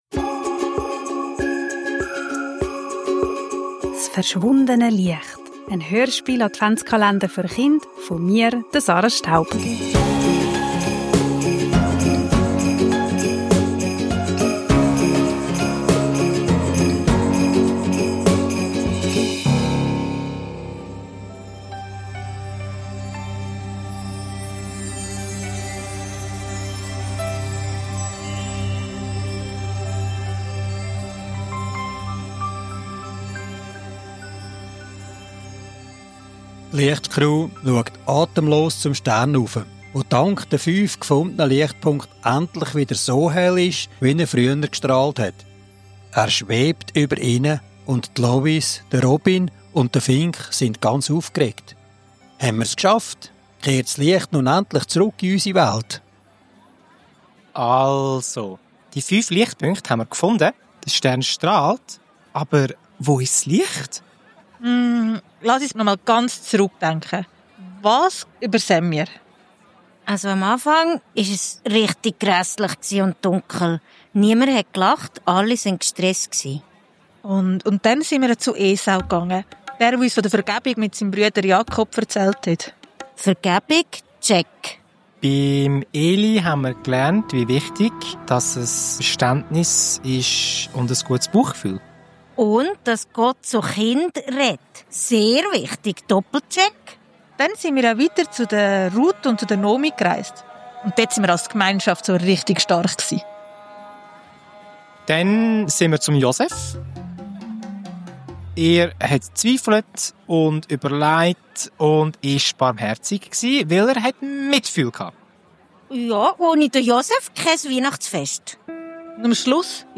Kinder, Advent, Hörspiel, Weihnachten, Kindergeschichte